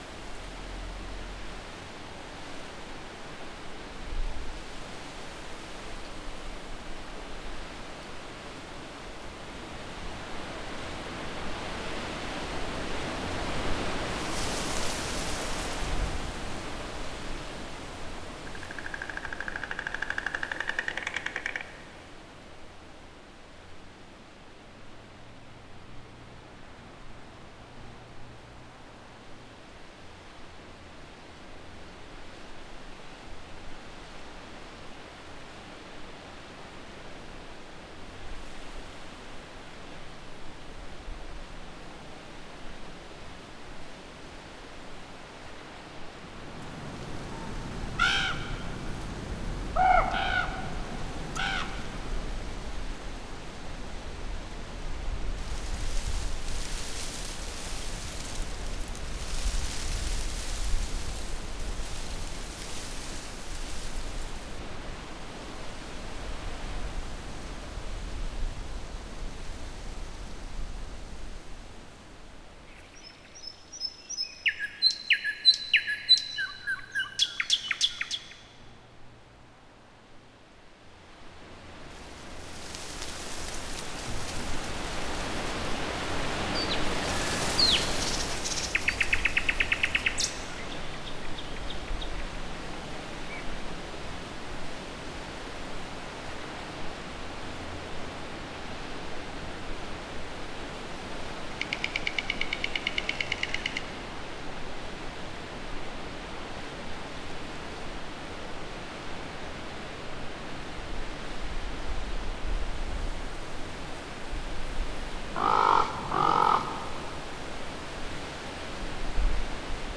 Farm_Atmos_02.wav